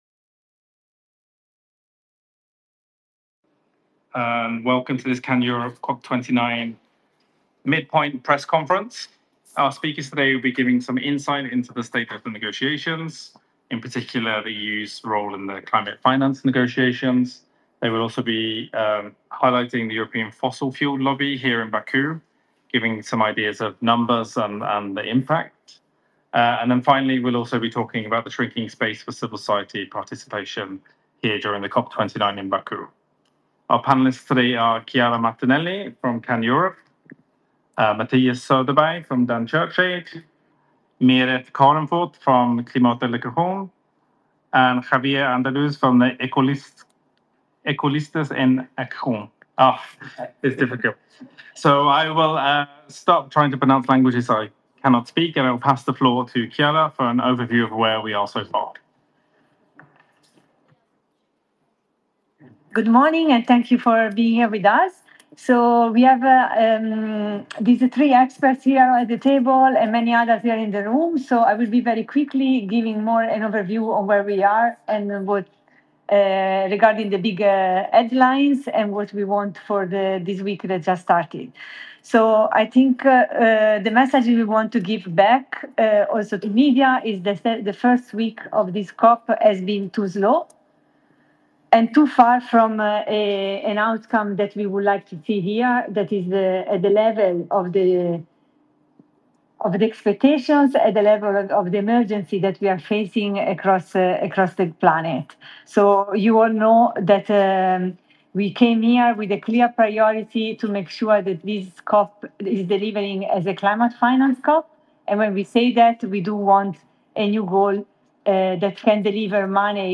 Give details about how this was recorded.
On 18 November 2024 CAN Europe organised a press conference during COP29 in Azerbaijan, where a panel of experts reflected the developments during the first week of the conference.